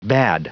Prononciation du mot bade en anglais (fichier audio)
Prononciation du mot : bade